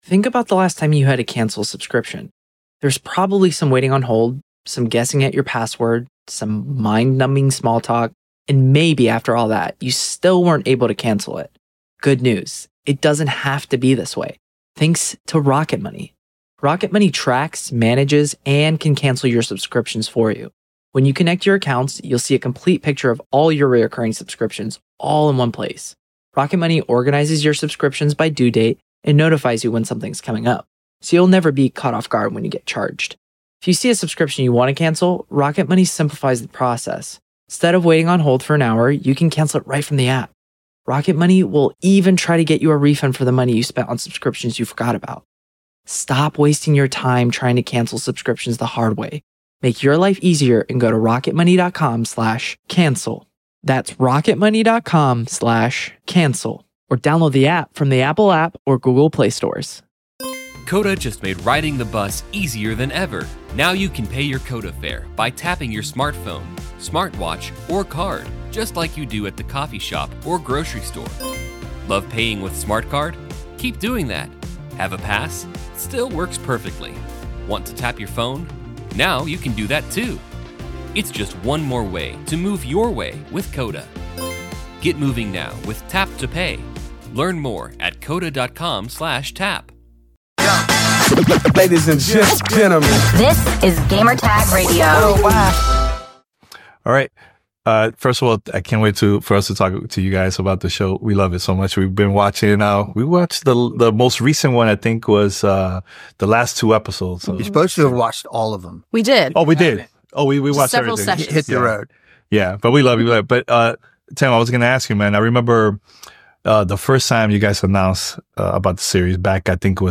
Secret Level Interview